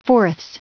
Prononciation du mot fourths en anglais (fichier audio)
Prononciation du mot : fourths